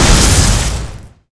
muyeong_explo.wav